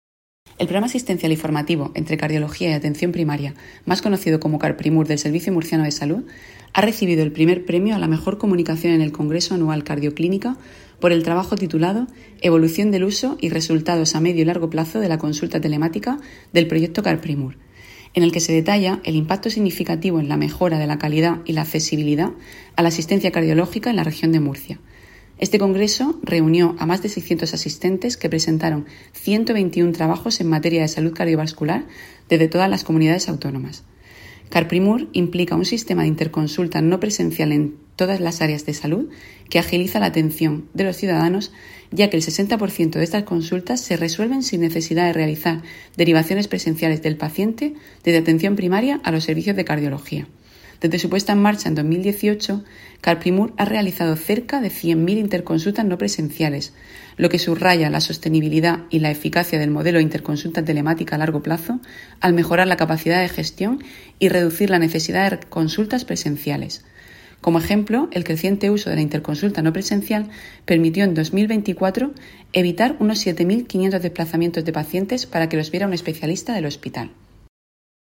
Sonido/ Declaraciones de la gerente del SMS, Isabel Ayala, sobre el premio concedido al programa CarPrimur.